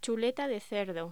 Locución: Chuleta de cerdo
voz